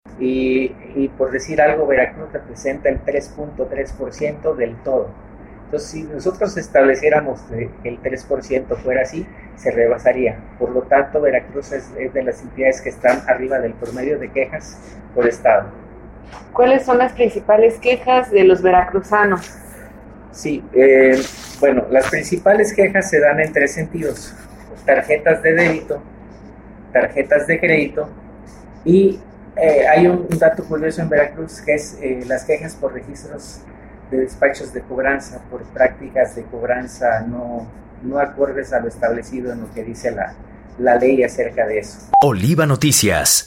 En entrevista, precisó que dichas quejas conforman el 40 por ciento de las reclamaciones recibidas en este 2022, en tanto que Veracruz, Xalapa y Boca del Río son los municipios que presentaron mayor número de controversias en defensa de las personas usuarias de productos servicios financieros.